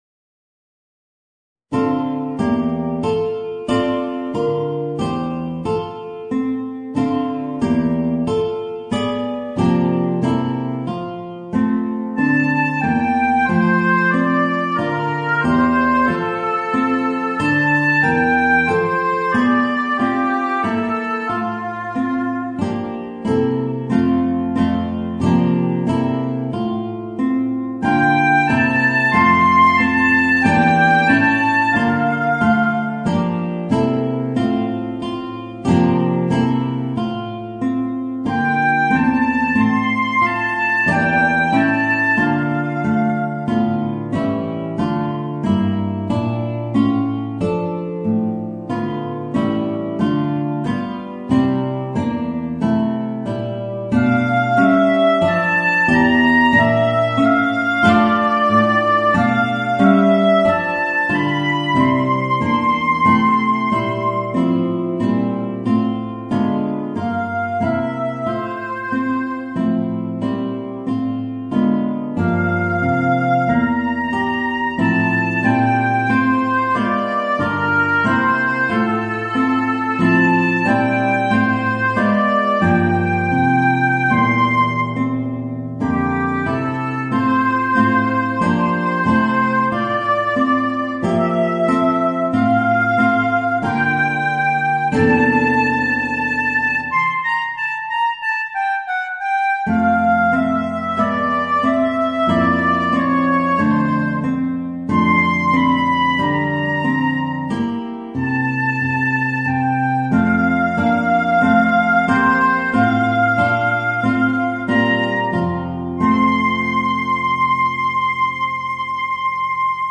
Voicing: Oboe and Guitar